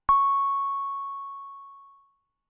Roland Juno 6 Rich pluck " Roland Juno 6 Rich pluck C6 ( C5 Rich pluck85127)
标签： CS harp6 MIDI音符-85 罗兰朱诺-6 合成器 单票据 多重采样
声道立体声